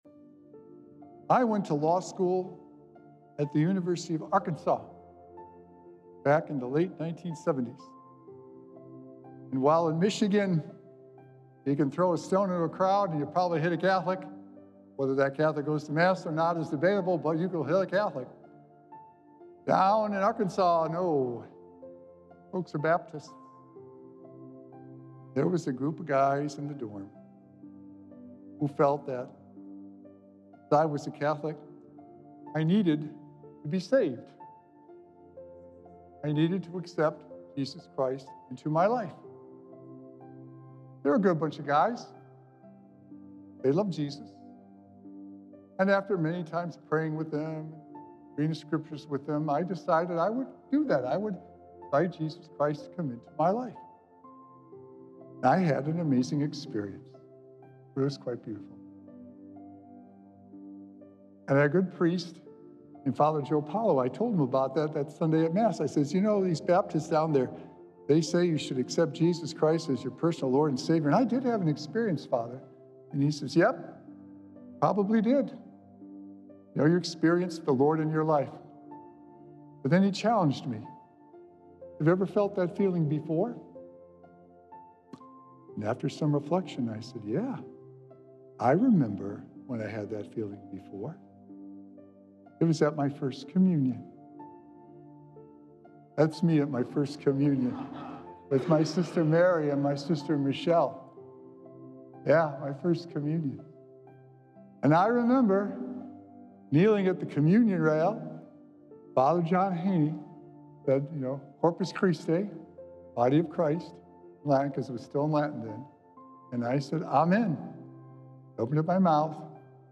Sacred Echoes - Why We Remember - Holy Thursday Homily
Recorded Live on Thursday, April 17th, 2025 at St. Malachy Catholic Church.